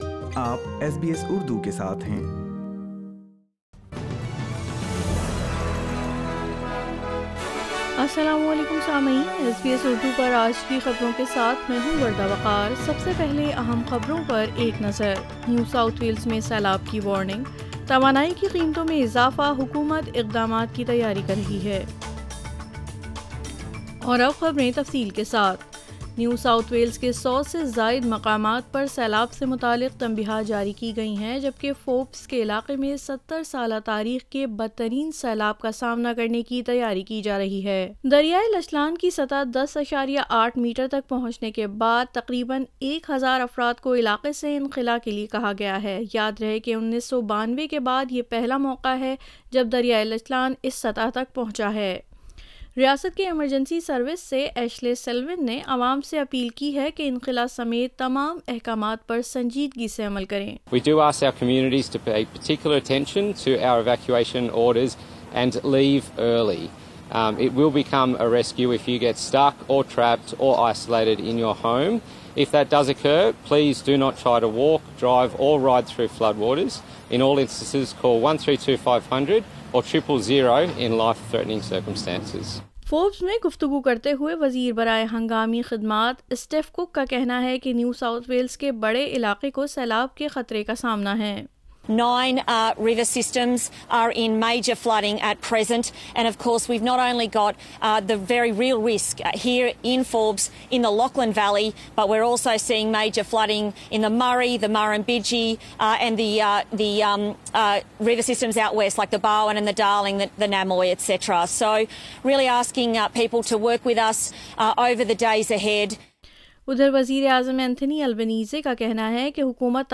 Urdu News Friday 04 November 2022